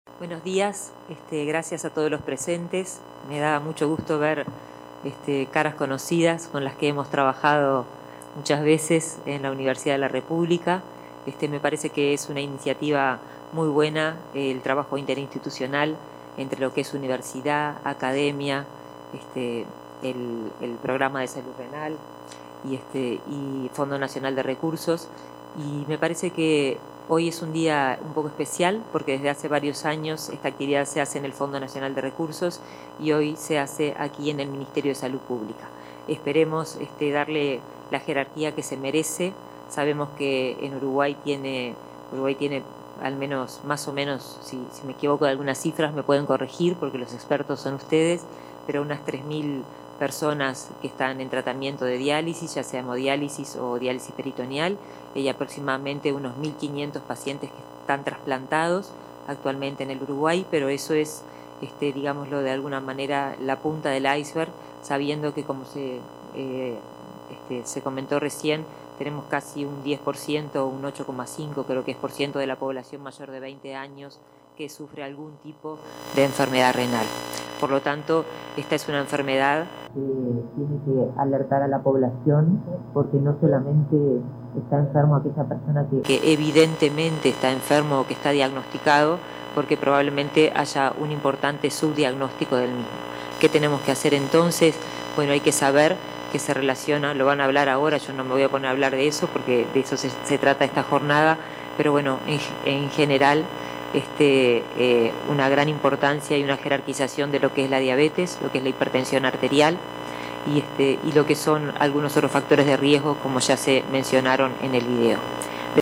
Palabras de la ministra de Salud Pública, Karina Rando
El Ministerio de Salud Pública realizó este jueves 16 una jornada por el Día Mundial del Riñón.